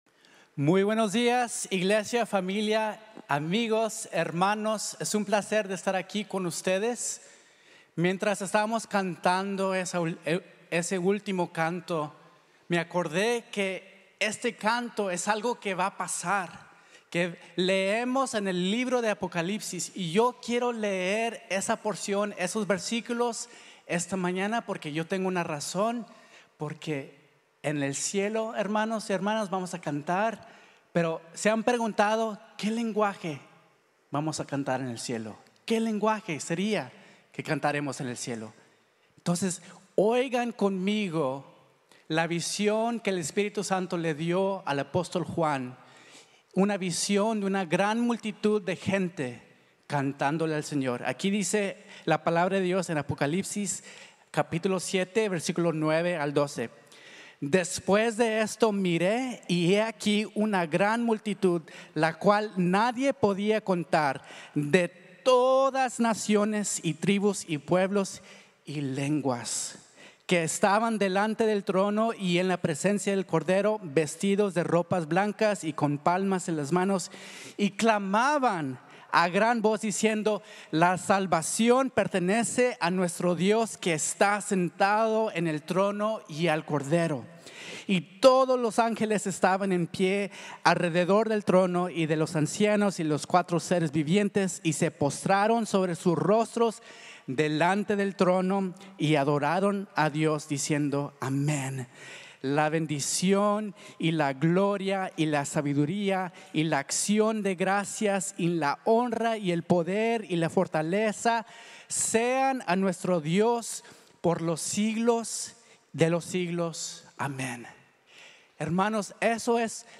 Dios como refugio | Sermón | Grace Bible Church